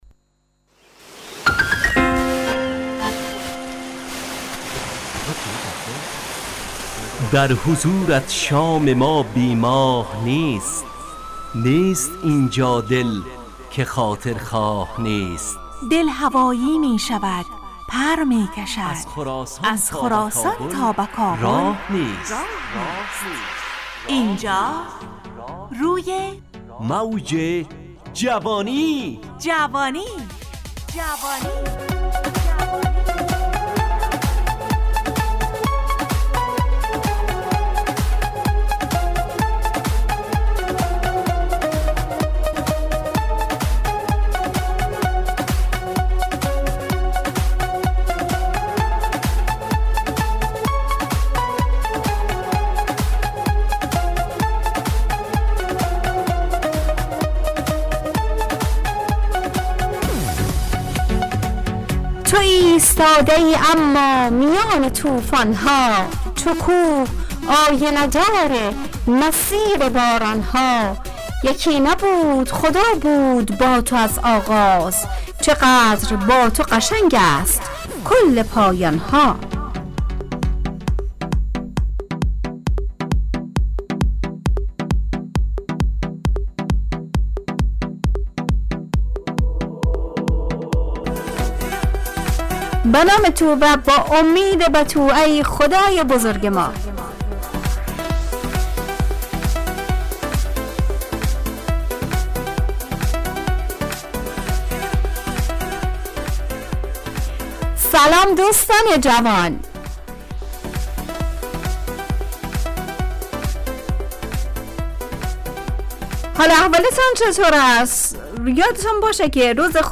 همراه با ترانه و موسیقی مدت برنامه 70 دقیقه .